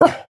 Minecraft Version Minecraft Version snapshot Latest Release | Latest Snapshot snapshot / assets / minecraft / sounds / mob / wolf / grumpy / bark3.ogg Compare With Compare With Latest Release | Latest Snapshot
bark3.ogg